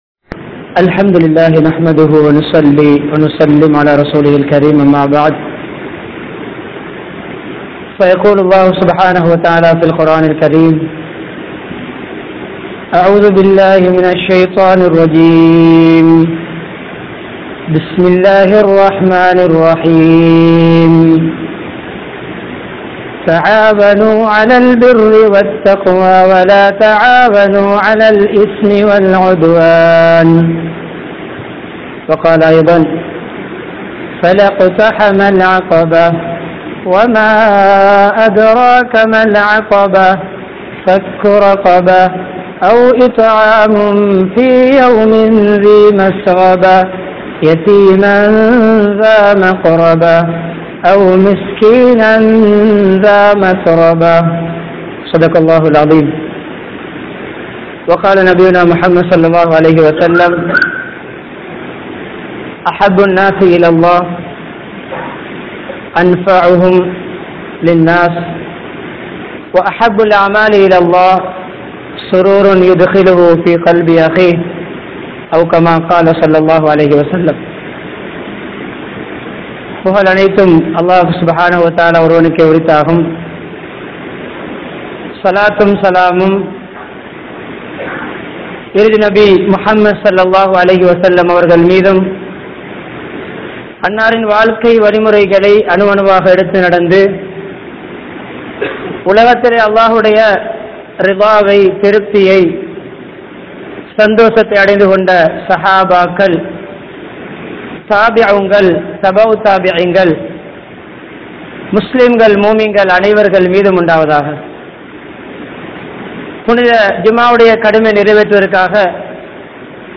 Pirarin Teavaihalai Nirai Veattrungal (பிறரின் தேவைகளை நிறைவேற்றுங்கள்) | Audio Bayans | All Ceylon Muslim Youth Community | Addalaichenai
Panadura, Gorakana Jumuah Masjith